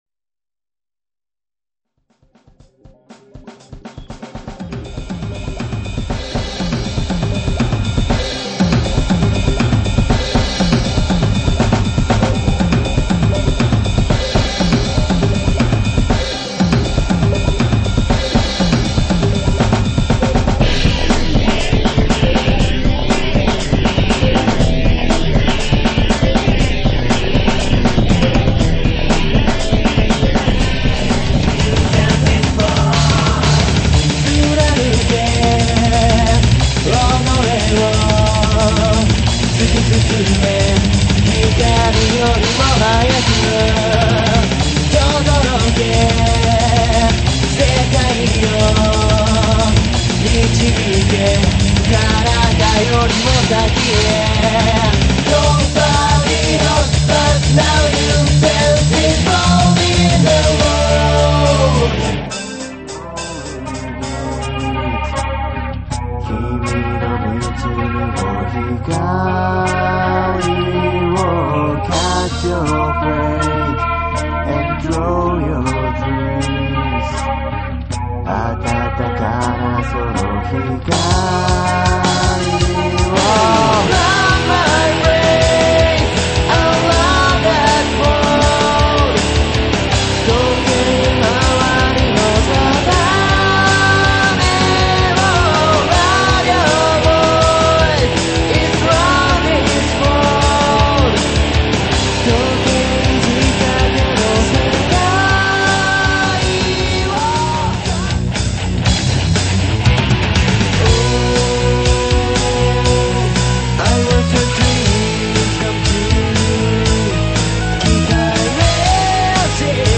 インストです。
曲調も超暗いし歌詞もなんか怖いしめちゃくちゃヘヴィーな上テンポが速い（BPM205で途中250のところあり）。
ヘヴィーというよりは非常にスラッシーな曲ですね。